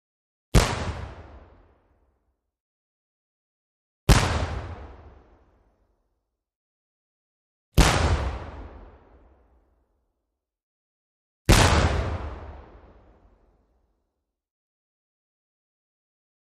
Medium Single Cannon Fire ( 4x ); Four Separate Cannon Fires. Mid-sized Cannon With Long Echo. Medium Close Perspective.